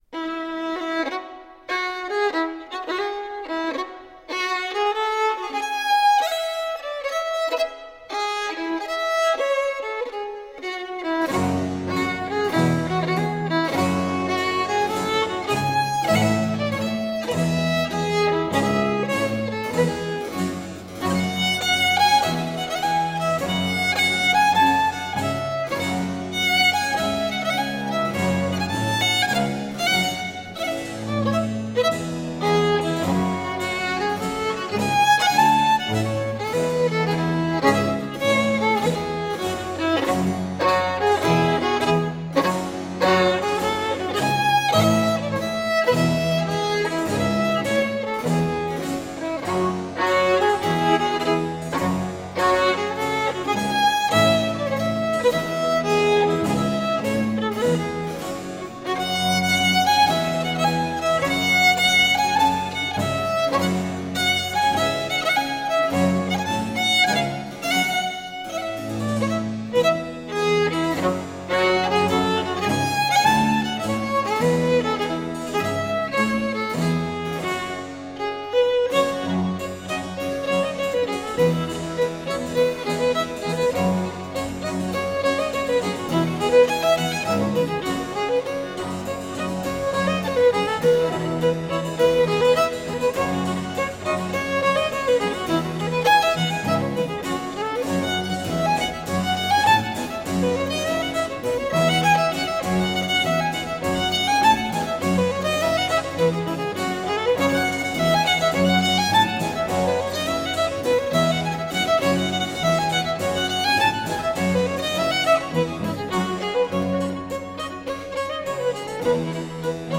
Early folk and celtic music..
Tagged as: World, Folk, Medieval, Folk, Celtic